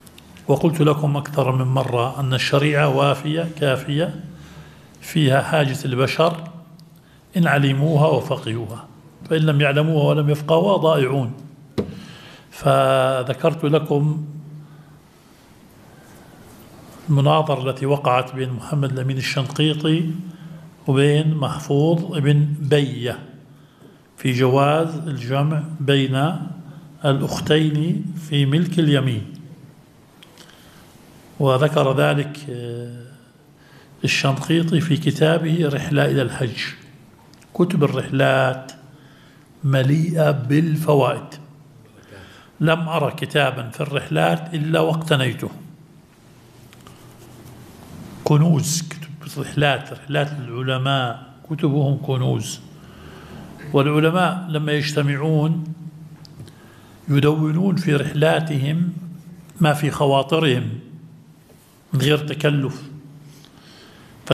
درس ١٤ – مبحث العام والخاص